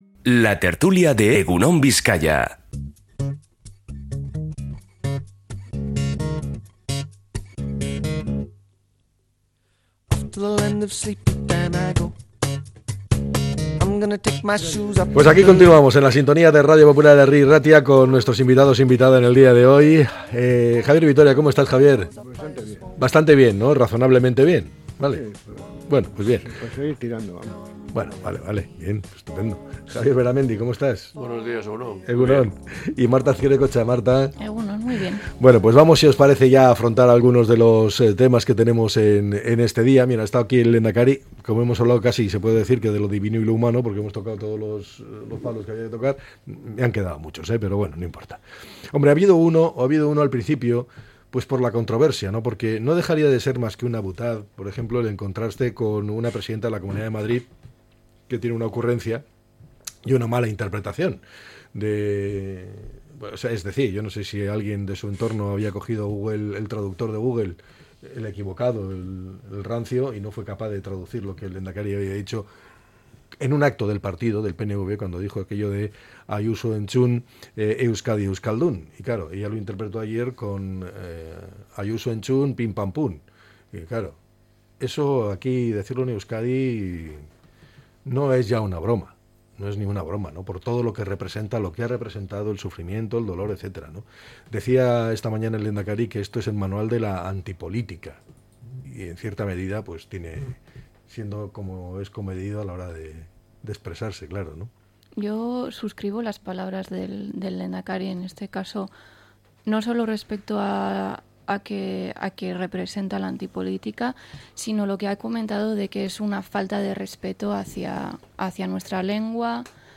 La Tertulia 30-09-25.